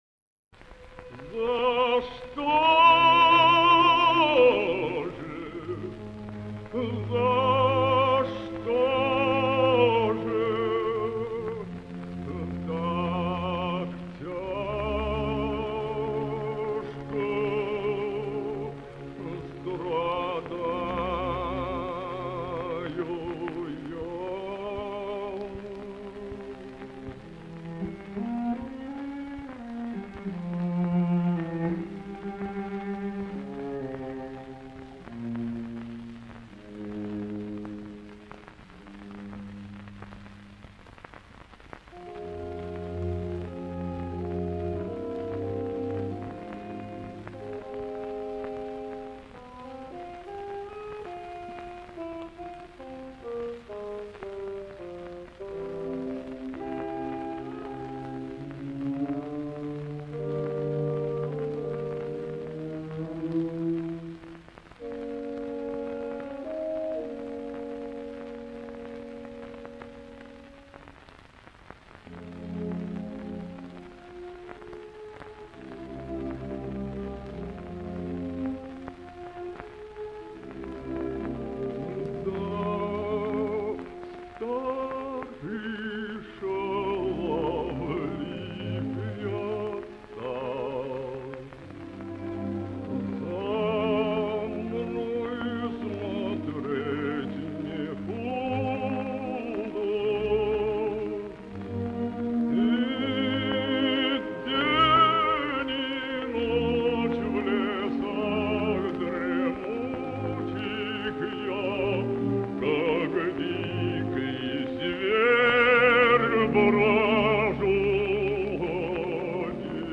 Bass
Tenor
Duet with orch.